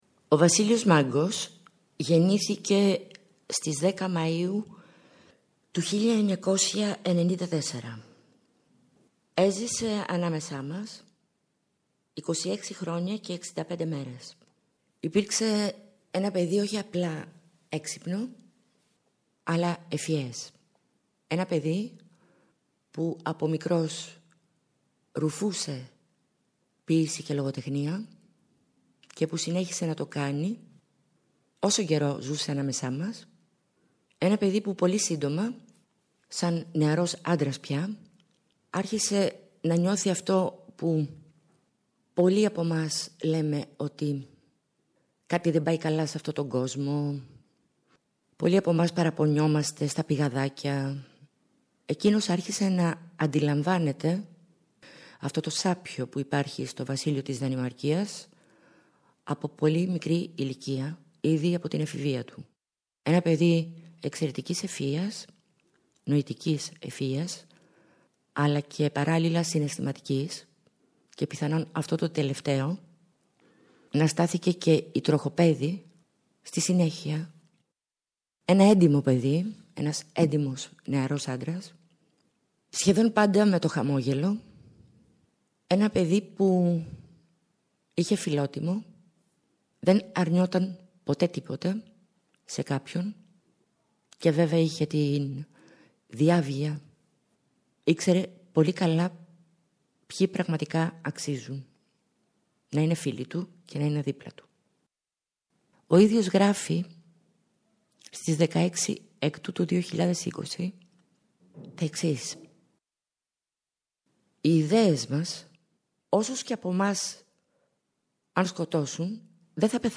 ακούγονται σπάνια ηχητικά τεκμήρια
Ντοκιμαντέρ